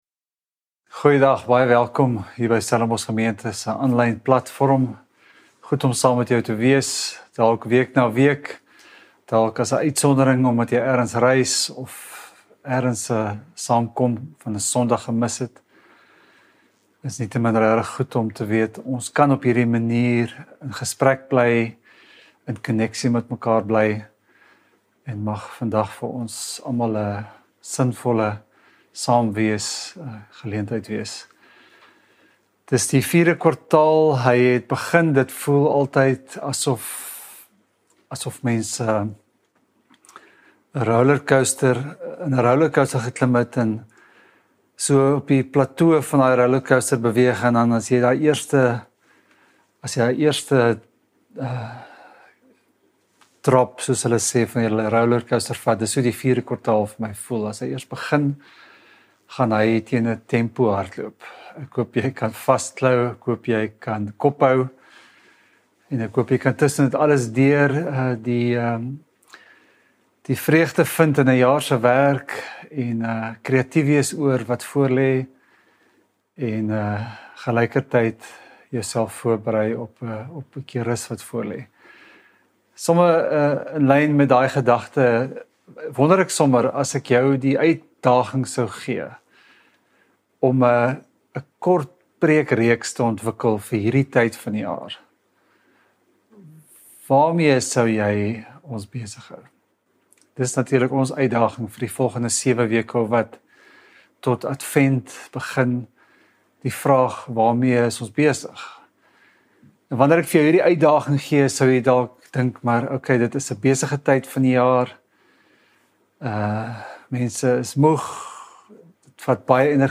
Stellenbosch Gemeente Preke 13 Oktober 2024 || God Is...